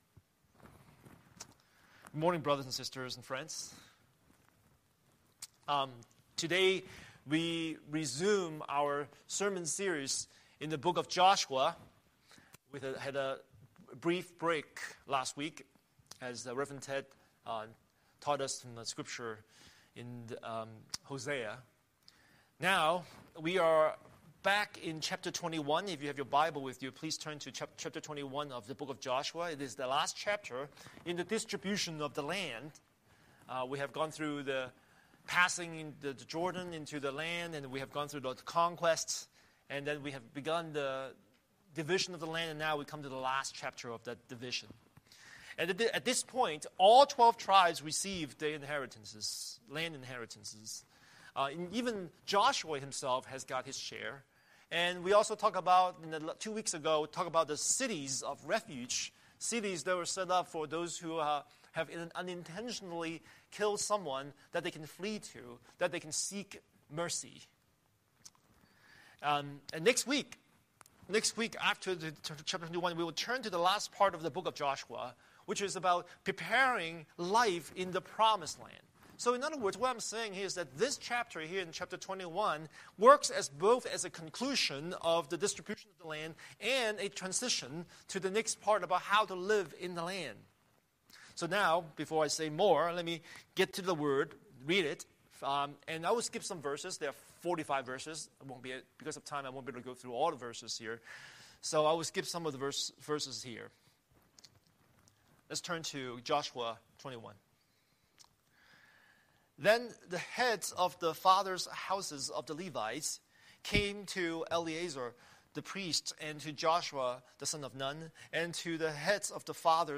Scripture: Joshua 21:1–45 Series: Sunday Sermon